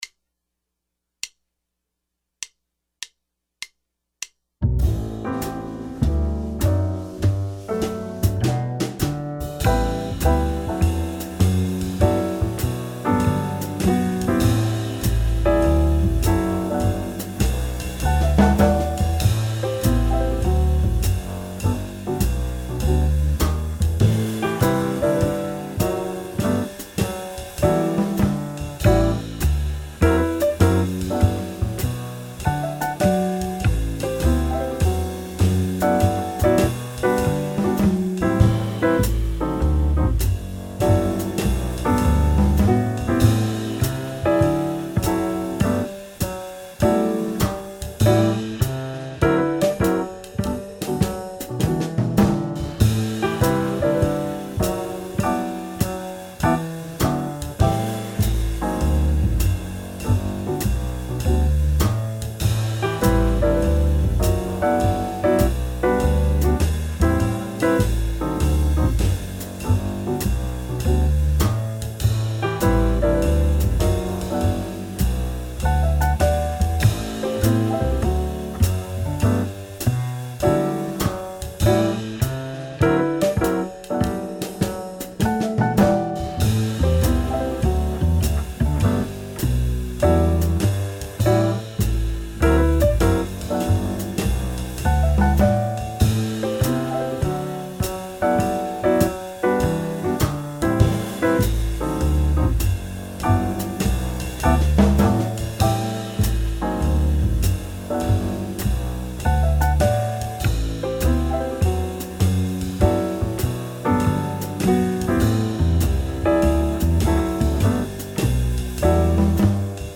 Major Jazz Guitar Lick Workout